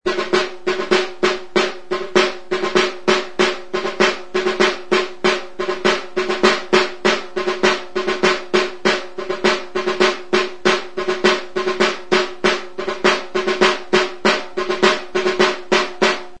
Membranophones -> Beaten -> Stick-beaten drums
Recorded with this music instrument.
Zurezko kaxa zilindrikoa eta larruzko bi mintz ditu.
Atzekaldeko mintzean tripazko soka du bordoitzat, zurezko tentsorearekin.